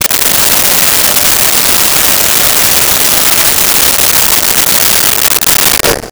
Blow Dryer 01
Blow Dryer 01.wav